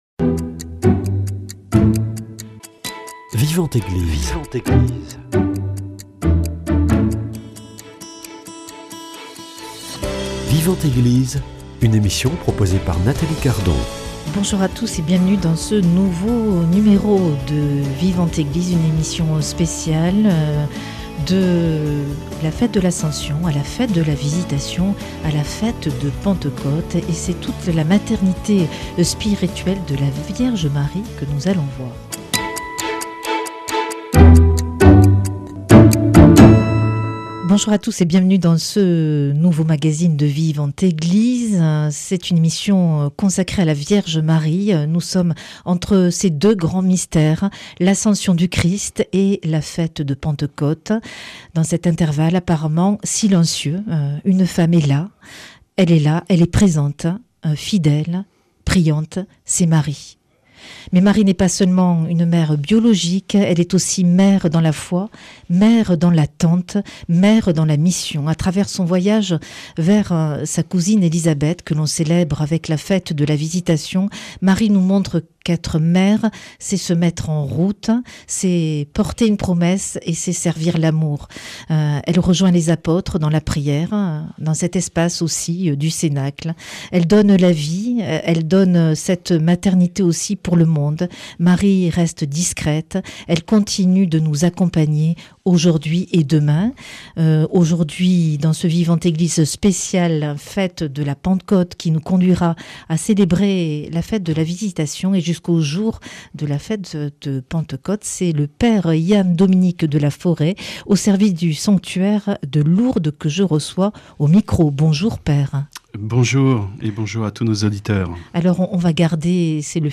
Invité : père